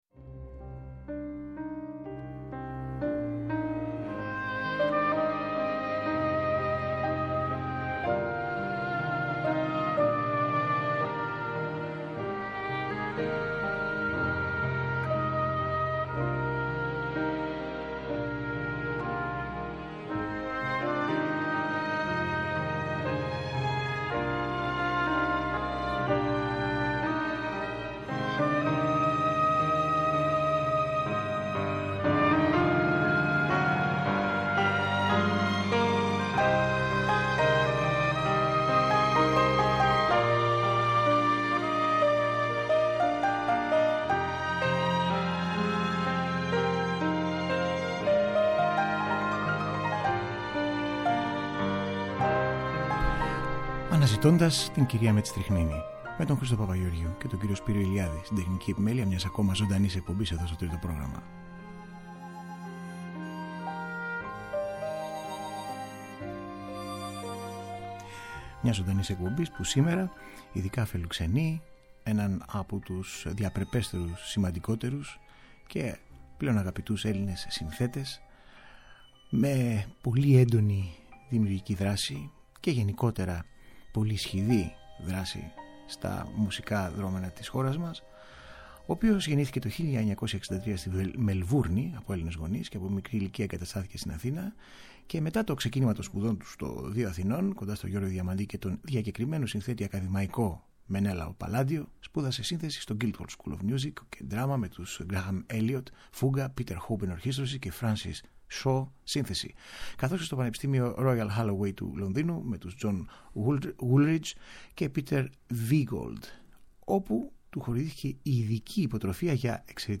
με τον συνθέτη ζωντανά στο στούντιο